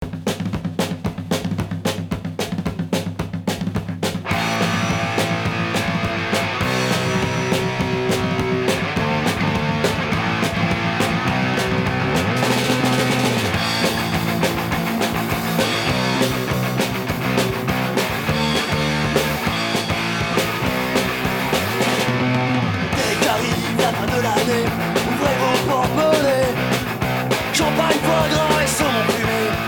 Street punk